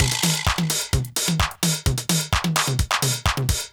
CLF Beat - Mix 1.wav